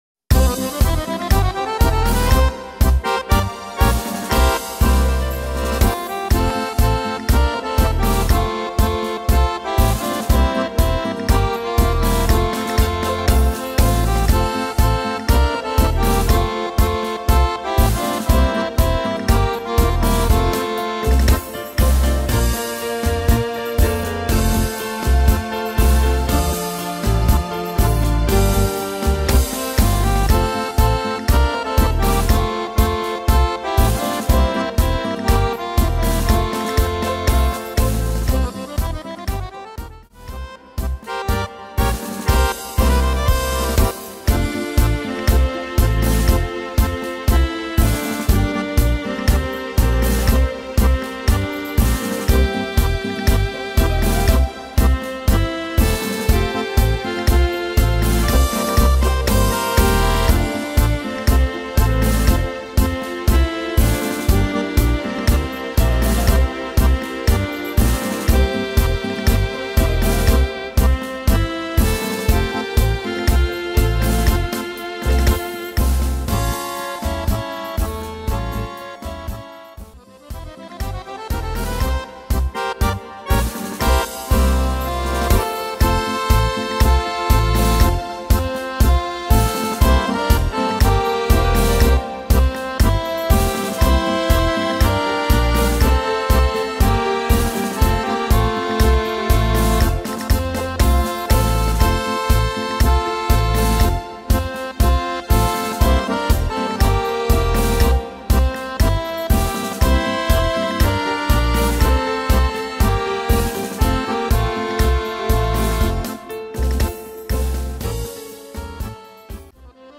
Tempo: 120-140 / Tonart: F-Dur